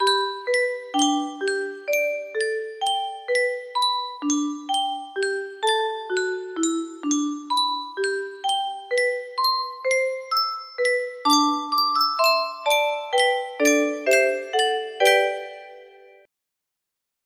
Clone Yunsheng Music Box - Someone's in the Kitchen with Dinah music box melody